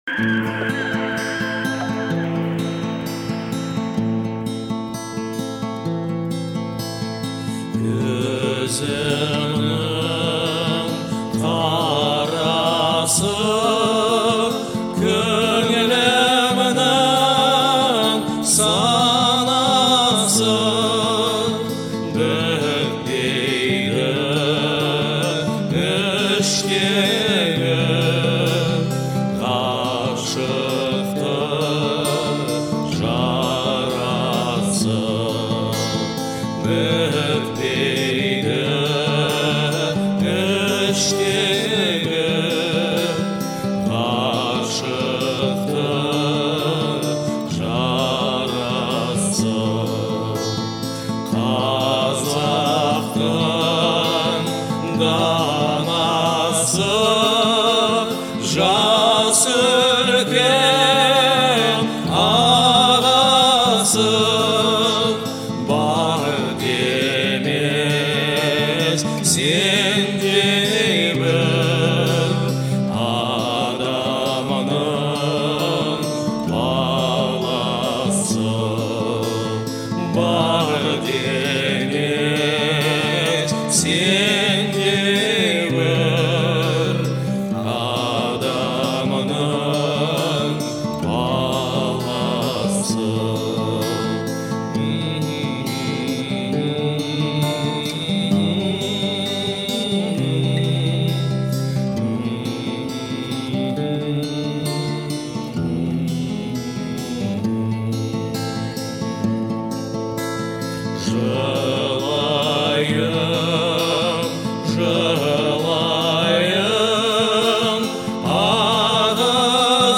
это романтическая песня в жанре казахского попа
его вокал пронизан искренностью и теплотой.